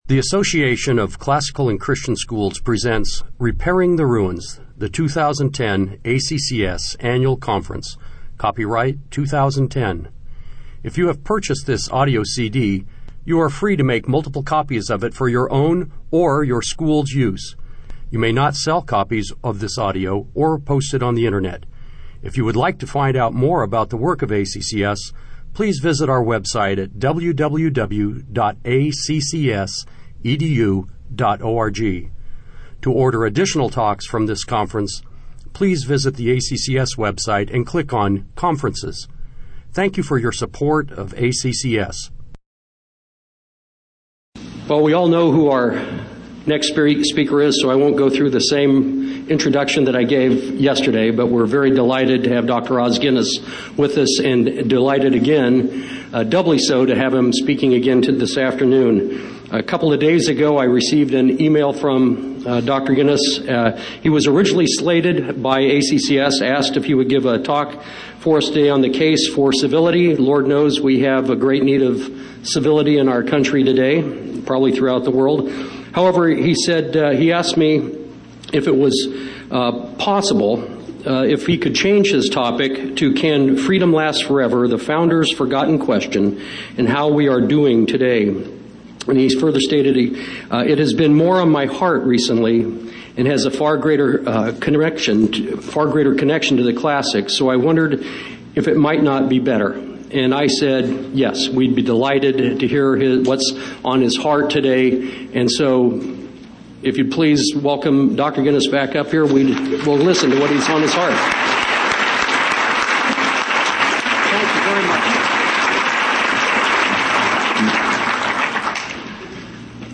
2010 Plenary Talk | 1:03:05 | All Grade Levels, Culture & Faith, Virtue, Character, Discipline